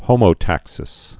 (hōmō-tăksĭs, hŏmō-)